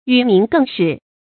與民更始 注音： ㄧㄩˇ ㄇㄧㄣˊ ㄍㄥ ㄕㄧˇ 讀音讀法： 意思解釋： 跟人民一起又有新的開端。指除舊布新。